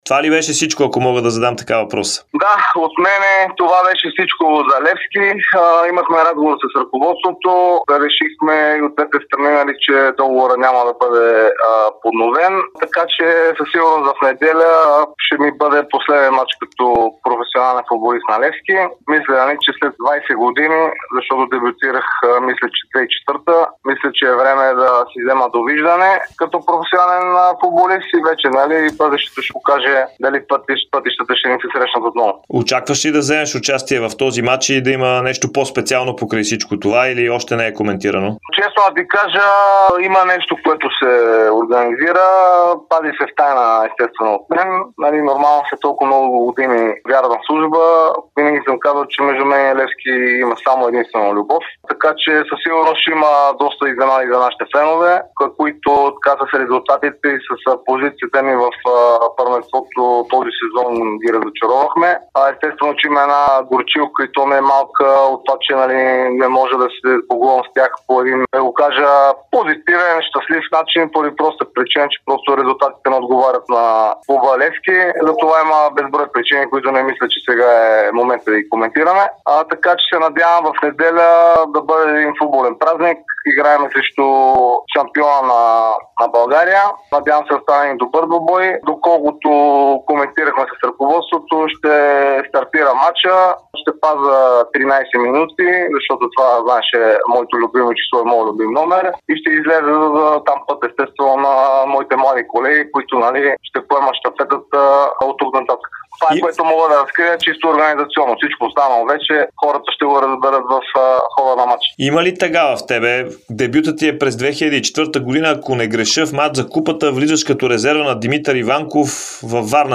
Капитанът на Левски Николай Михайлов даде ексклузивно интервю пред Дарик радио и dsport, в което обяви, че няма да удължи договора си със „синия“ клуб... (23.05.2024 16:31:22)